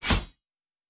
pgs/Assets/Audio/Fantasy Interface Sounds/Weapon UI 04.wav at master
Weapon UI 04.wav